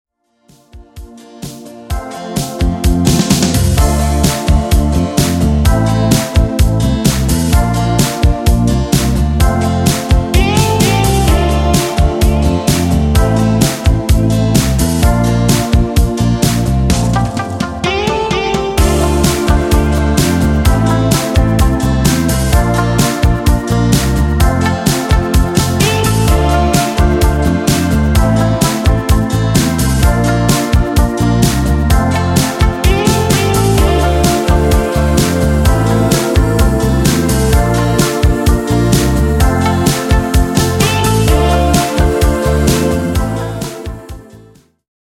w wersji instrumentalnej dla wokalistów